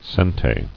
[sen·te]